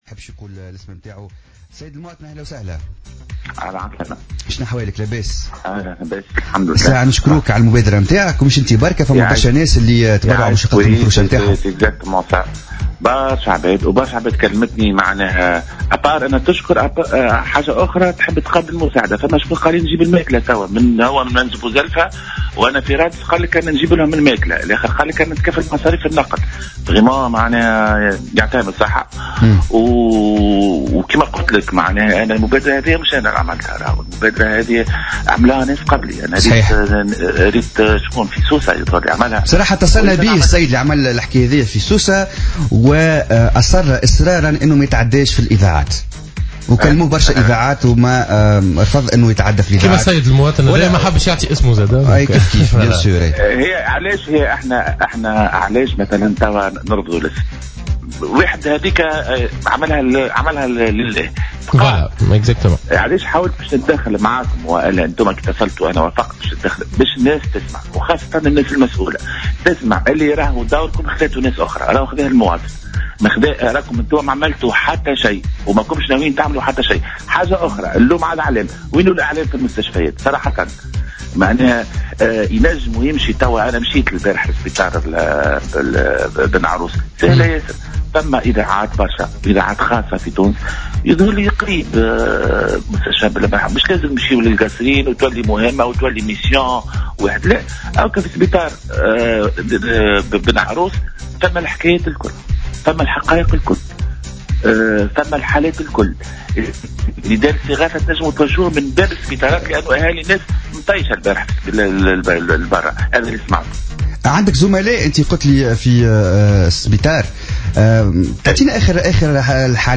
وقال أحد المتطوعين في تصريح ل"الجوهرة أف أم" إنه تلقى اتصالات من مواطنين آخرين عبروا عن رغبتهم كذلك في المساعدة من خلال تقديم الأكل و التكفل بمصاريف التنقل.